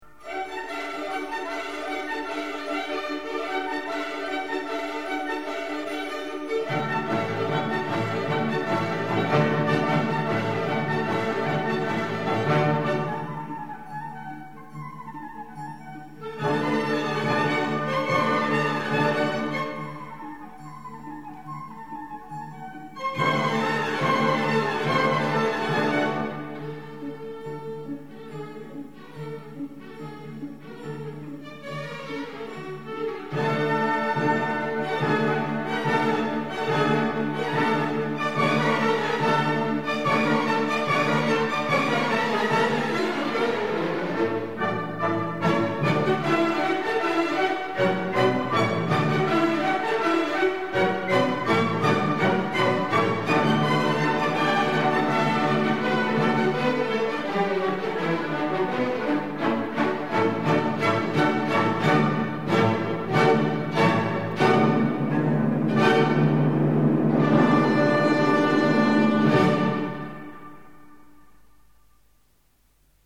Oeuvres symphoniques